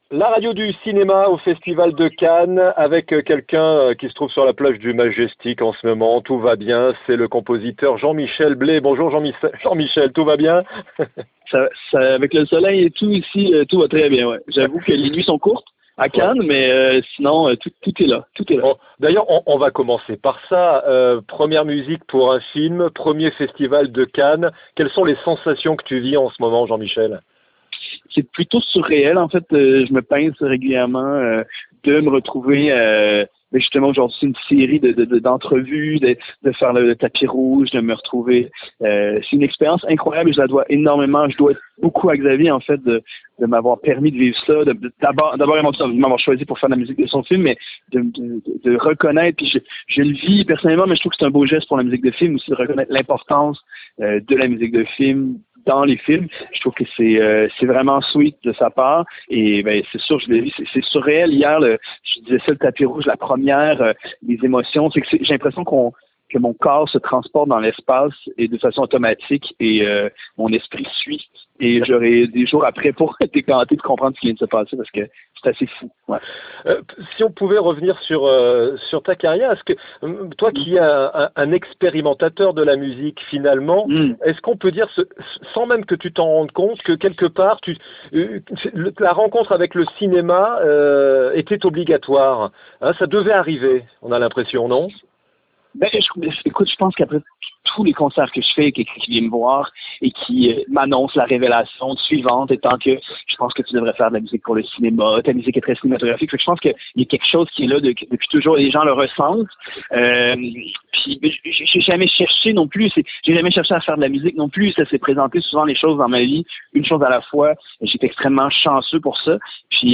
3. Jean-Michel BLAIS - Pianiste et compositeur du thème principal du film Matthias & Maxime de Xavier Dolan
Interview